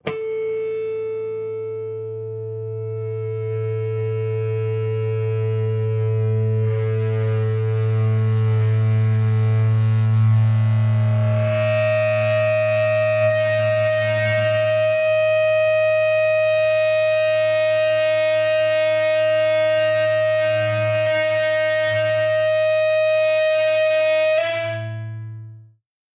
Звук электрогитары фонит в колонках